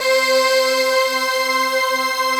Index of /90_sSampleCDs/Optical Media International - Sonic Images Library/SI1_Breath Choir/SI1_Soft Breath